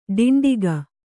♪ ḍiṇḍiga